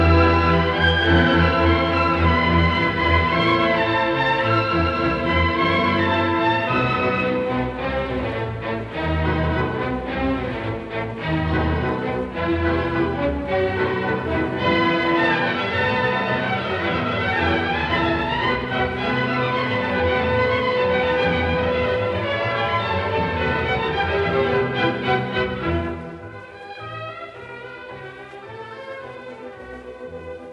"enPreferredTerm" => "Musique classique"